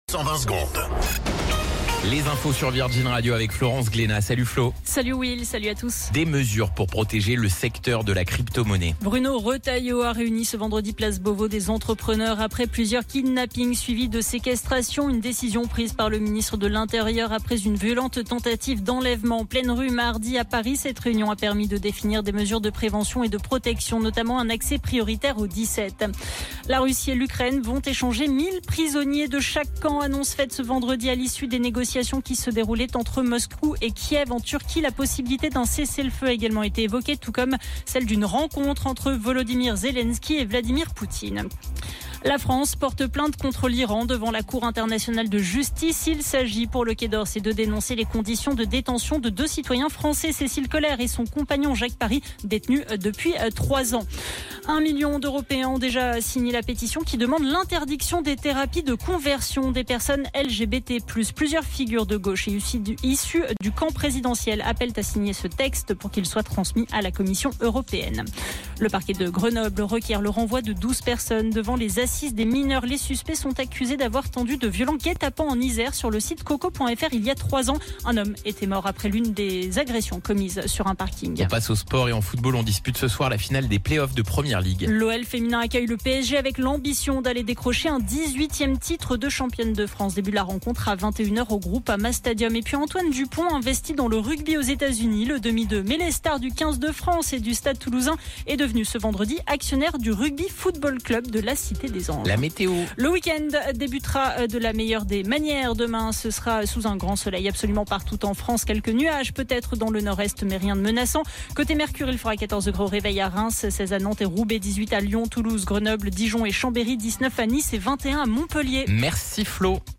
Flash Info National 16 Mai 2025 Du 16/05/2025 à 17h10 .